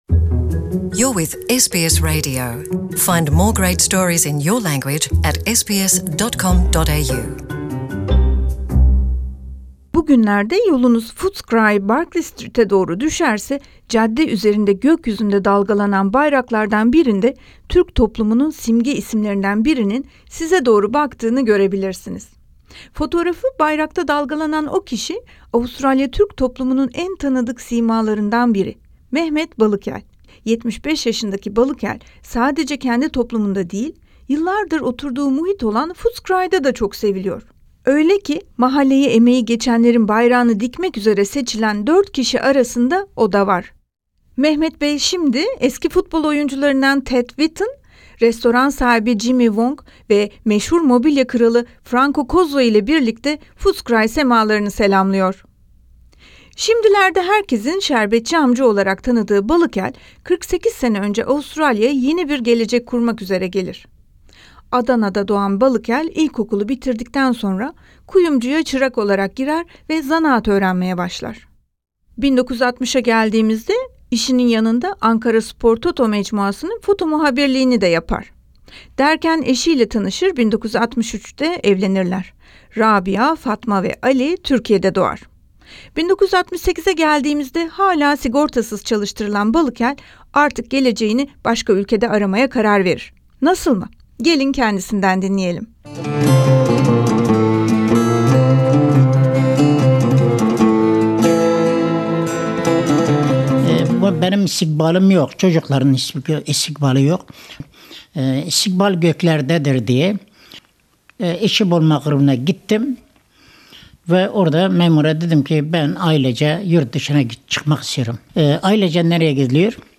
röportaj